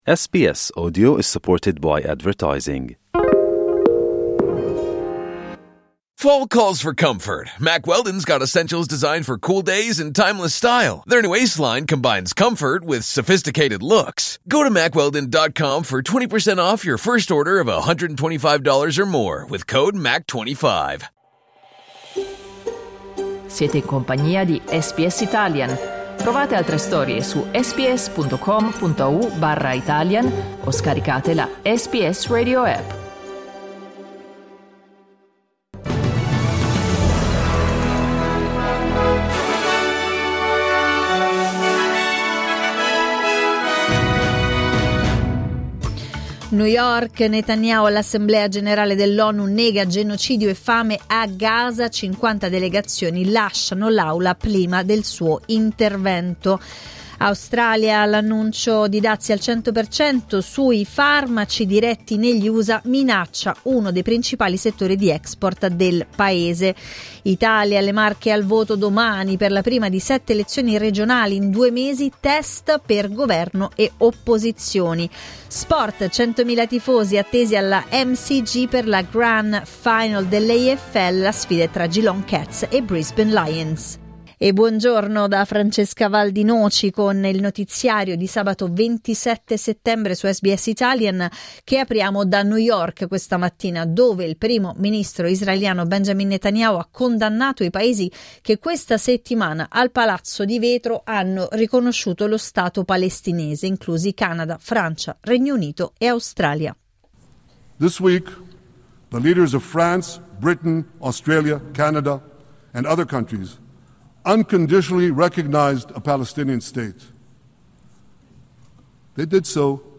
Giornale radio sabato 27 settembre 2025
Il notiziario di SBS in italiano.